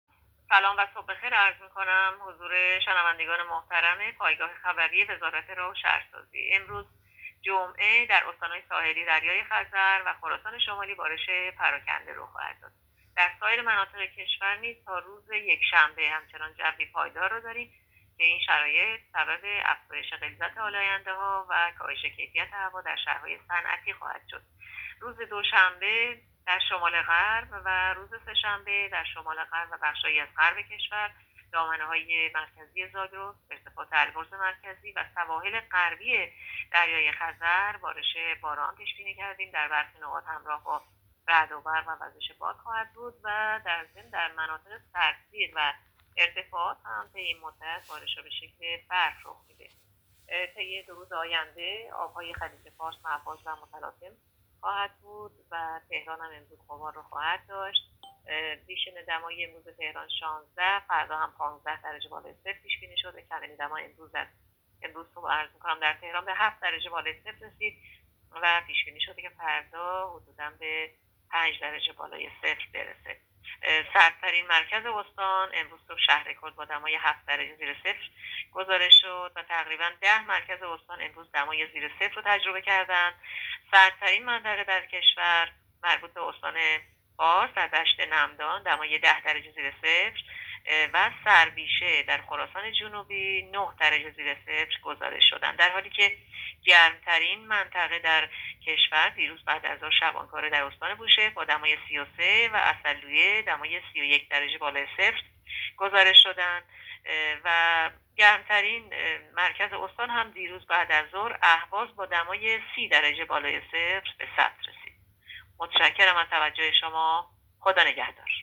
گزارش رادیو اینترنتی پایگاه‌ خبری از آخرین وضعیت آب‌وهوای ۷ آذر؛